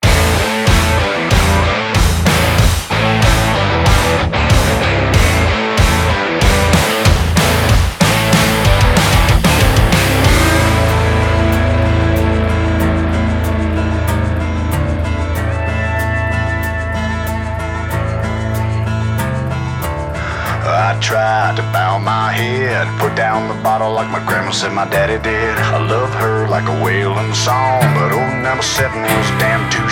• Outlaw Country